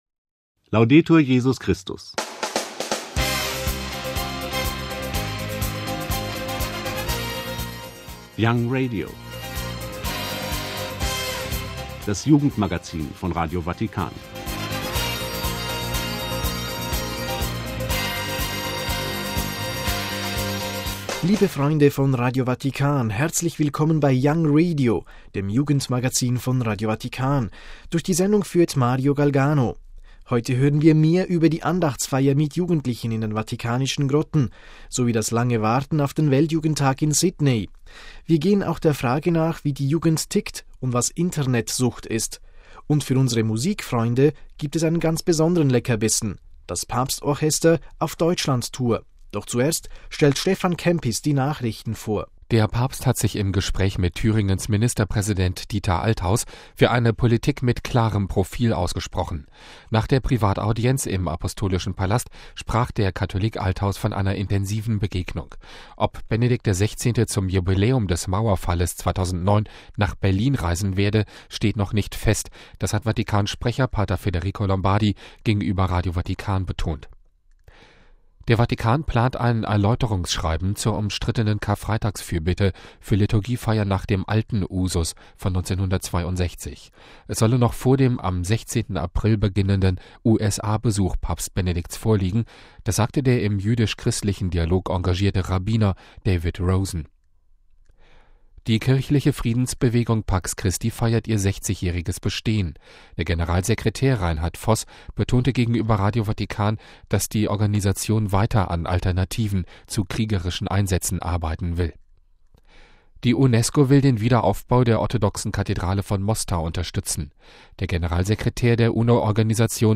YOUNGRADIO - Das Jugendmagazin von Radio Vatikan